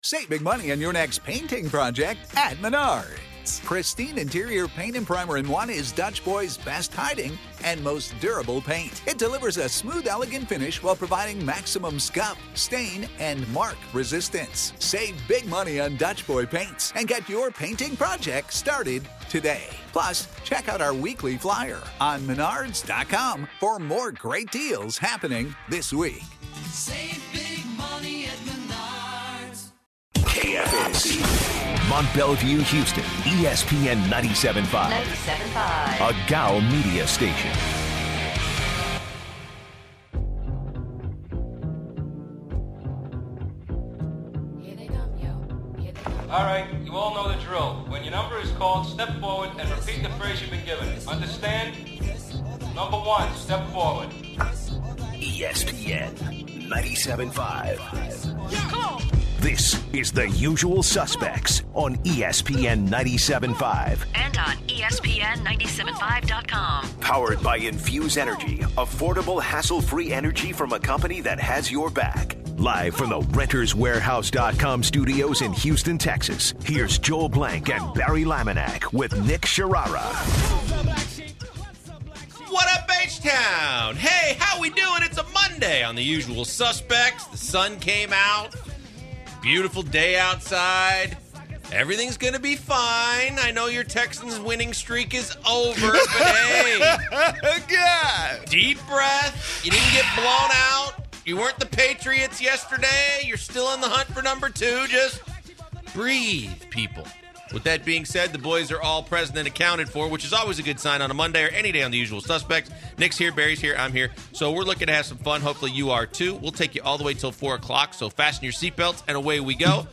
The guys talk about the Texans’ upsetting loss to the Colts and have a lot of callers that want to chime in. They dive deeper into Demaryius Thomas’ contributions to the team and Jadeveon Clowney’s game-ending penalty.